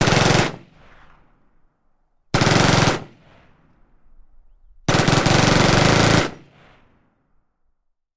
На этой странице собраны реалистичные звуки стрельбы из Минигана.
Выстрелы с паузами